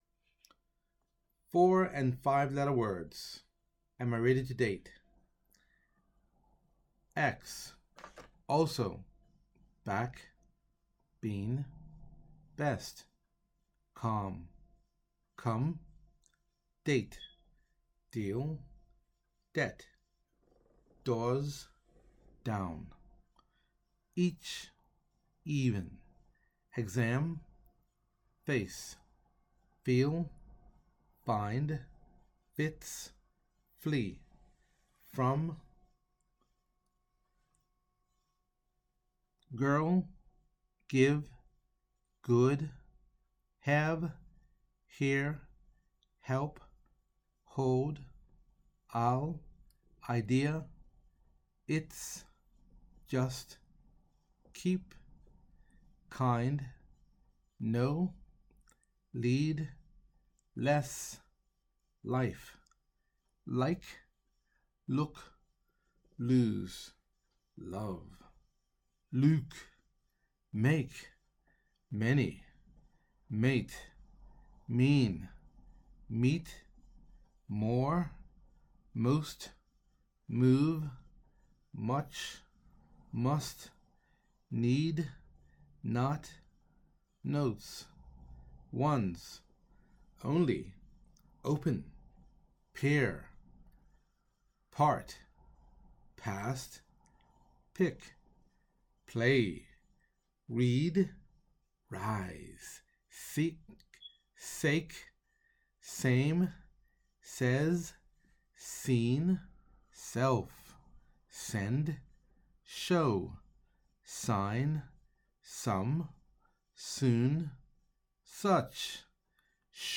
Below the vocabulary list and audio pronunciation for the presentation “Am I ready for dating and marriage” for English learners.